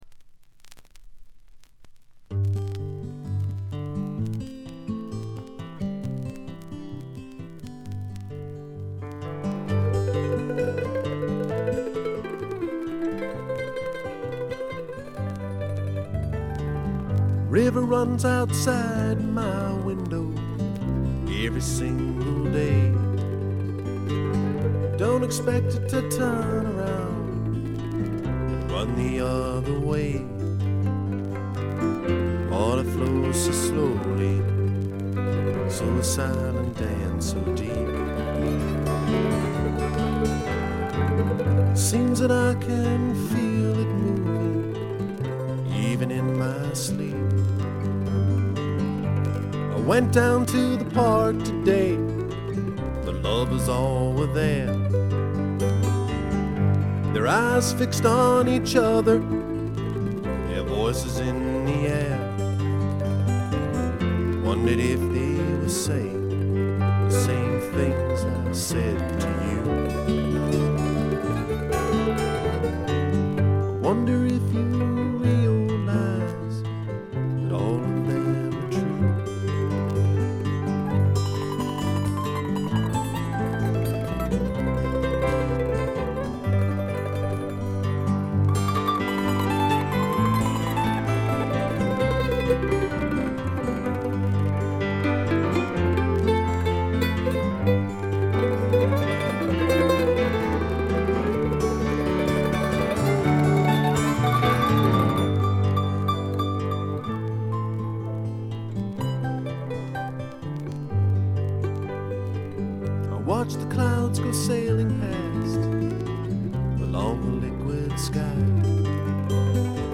軽微なチリプチが出てはいますが気になるほどのノイズは無いと思います。
試聴曲は現品からの取り込み音源です。
Guitar, Vocals
Drums
Violin, Vocals
Pedal Steel